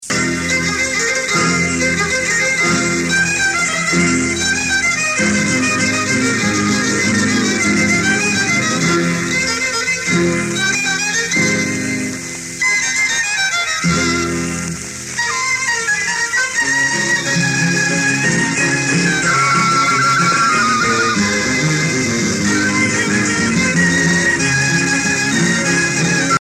danse : huayno (Pérou)
Pièce musicale éditée